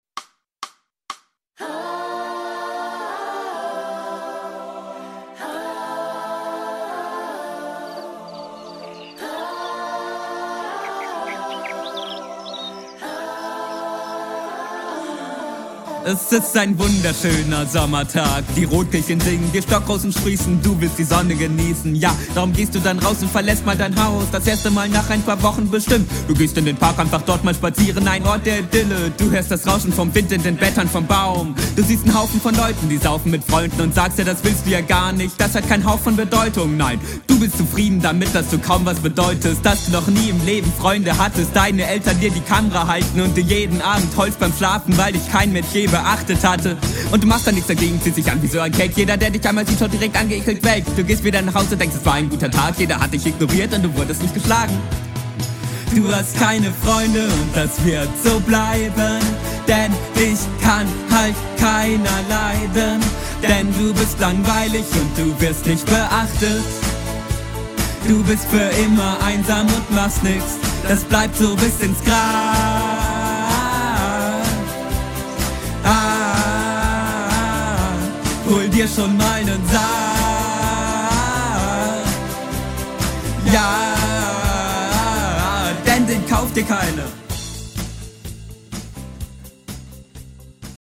Lol wasn beat, sind wir hier beim Karneval? na dann alaaf und helau...hab ich mir …